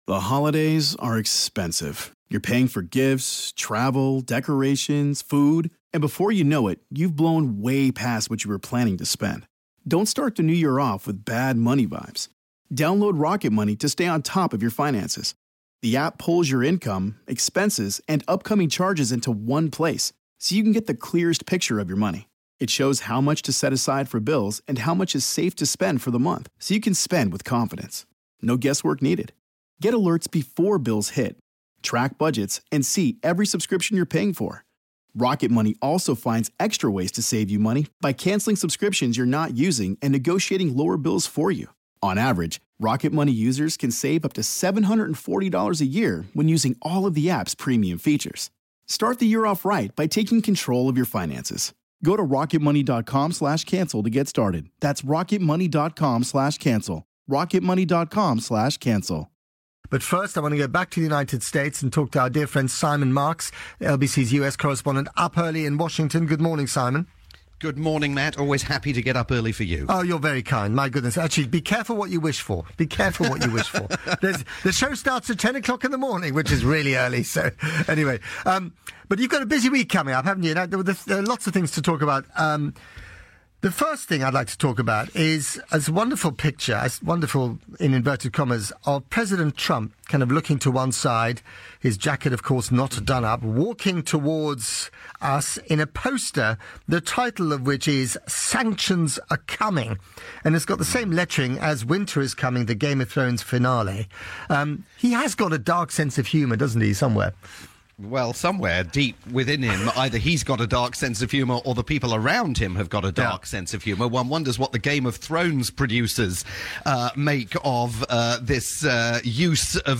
live conversation with Matt Frei on the UK's LBC.